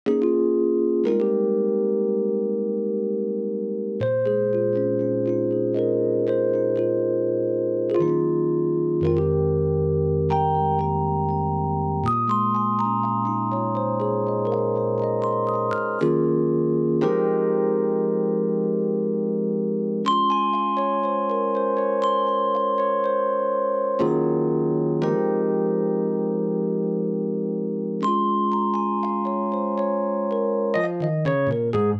13 rhodes A1.wav